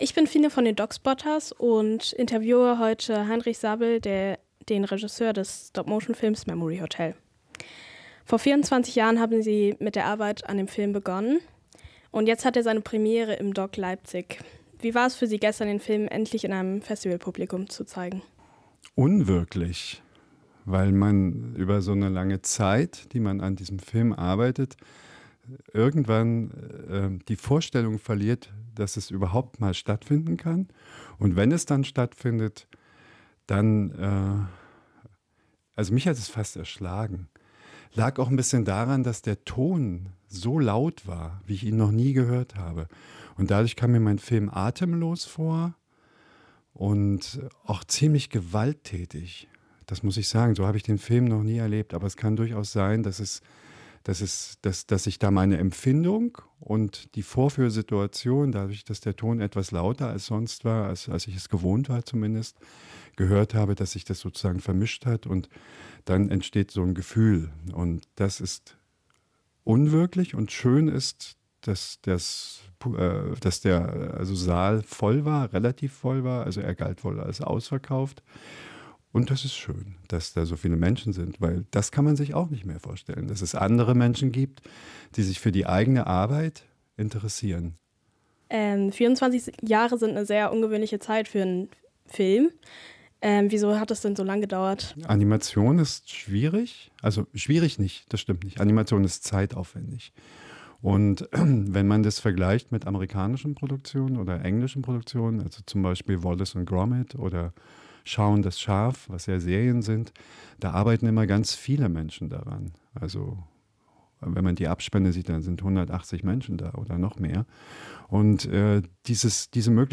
Von DOK Spotters 2024Audio, Interview